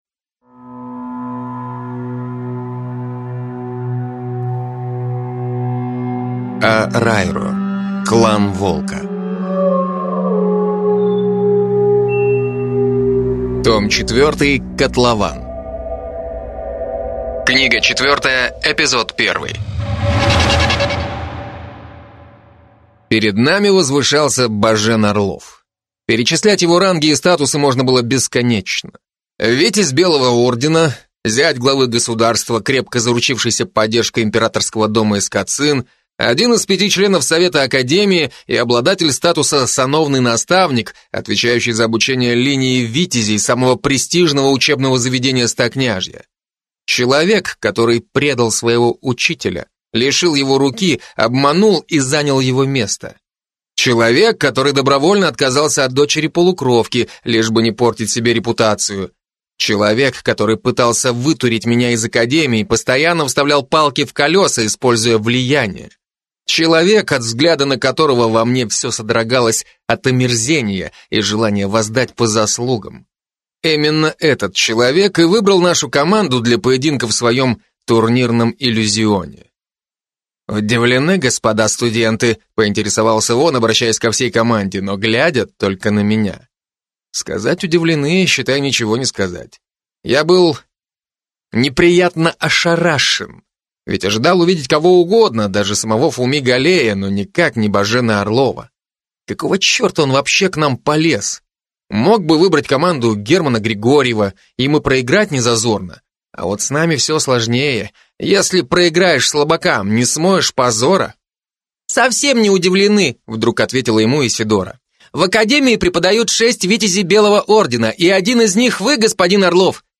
Аудиокнига ⚒Попаданцы, Магическая школа ✔4 книга из 6 в серии «Клан Волка» После турнира Военные Дома встали в очередь, чтобы заманить меня на службу.